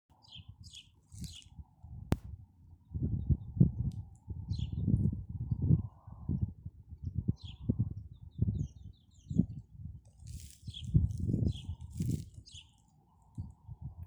Birds -> Sparrows ->
Tree Sparrow, Passer montanus
Ziņotāja saglabāts vietas nosaukumsSigulda